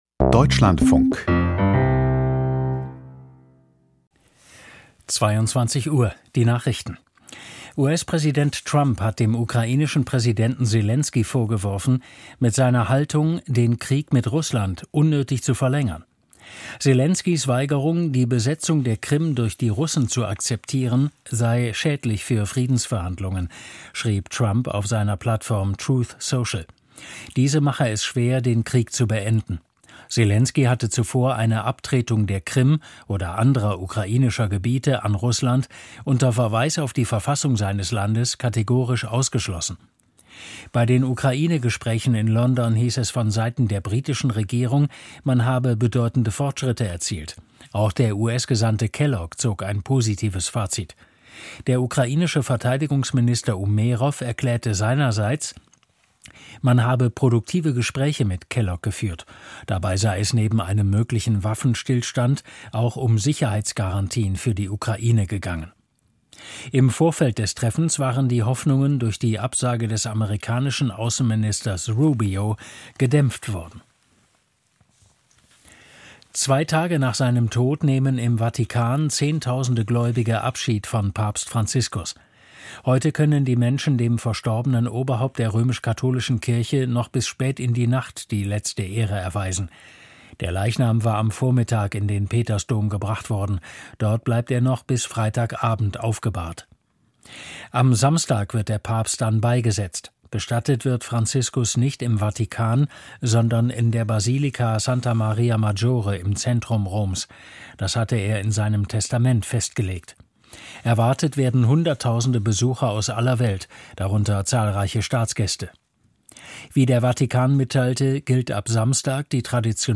Die Deutschlandfunk-Nachrichten vom 23.04.2025, 22:00 Uhr